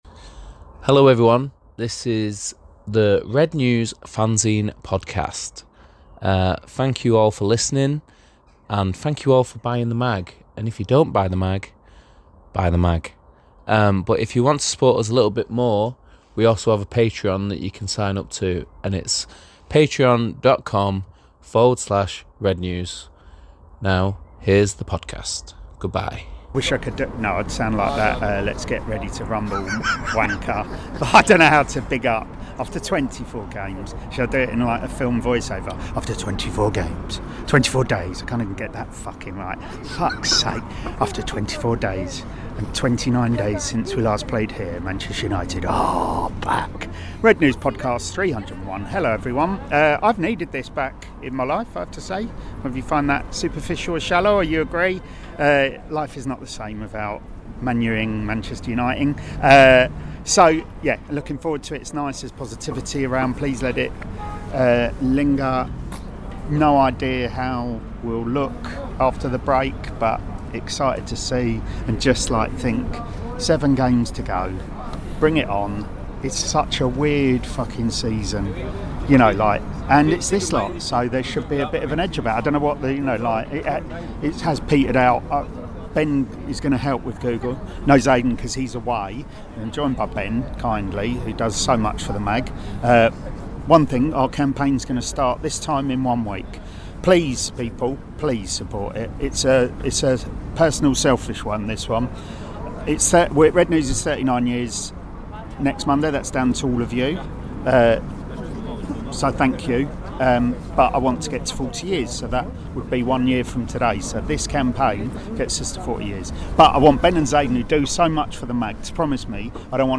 The independent, satirical Manchester United supporters' fanzine - for adults only, contains expletives, talks MUFC, or not at times, as we are giddy at the ground before the match, then annoyed and frustrated after it back in the pub.